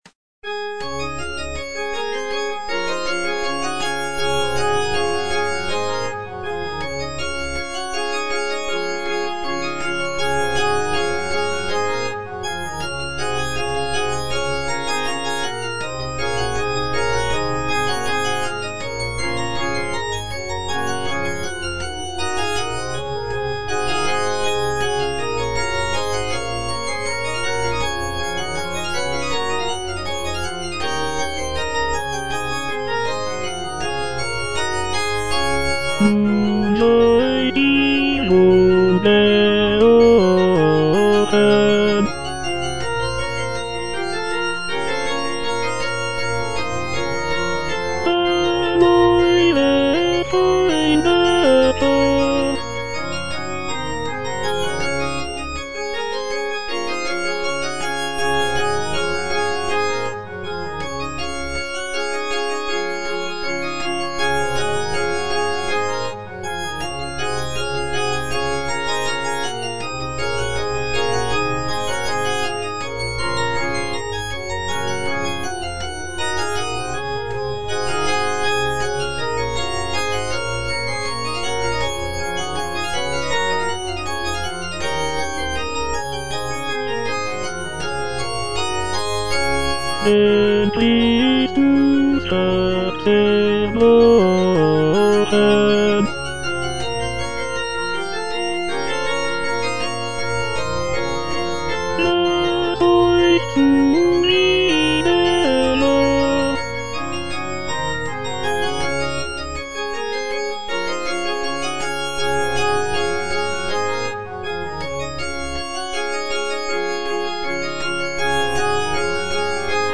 Choralplayer playing Christmas Oratorio BWV248 - Cantata nr. 6 (A = 415 Hz) by J.S. Bach based on the edition Bärenreiter BA 5014a
J.S. BACH - CHRISTMAS ORATORIO BWV248 - CANTATA NR. 6 (A = 415 Hz) 64 - Nun seid ihr wohl gerochen - Tenor (Voice with metronome) Ads stop: